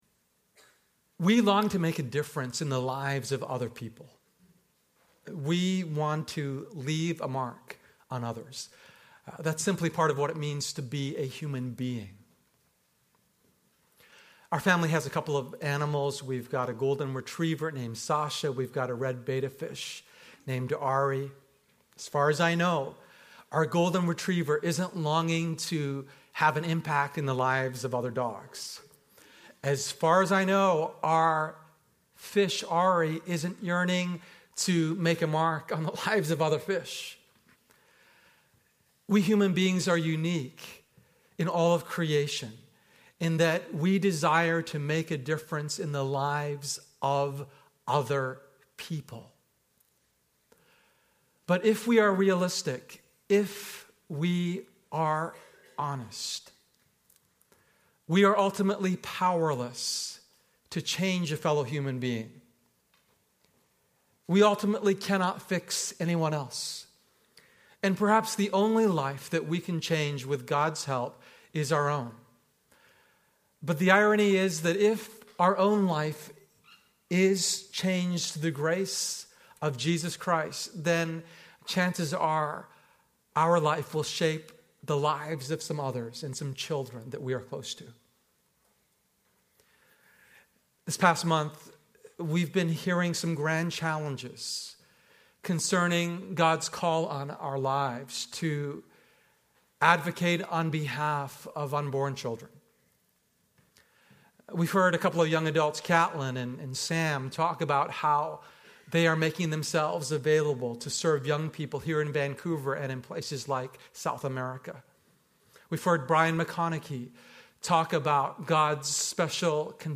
Episode from Tenth Church Sermons
public ios_share Tenth Church Sermons chevron_right Follow Me, as I Follow Christ Feb 8, 2015 A pastor explores how transformed character and daily example shape children more than techniques. He discusses guarding home purity, modeling sexual fidelity, and creating a safe environment for a son. He urges trusting God for provision, cultivating a servant heart, and learning to be less anxious.